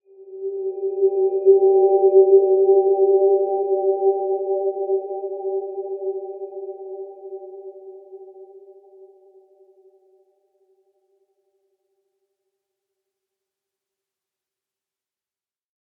Dreamy-Fifths-G4-f.wav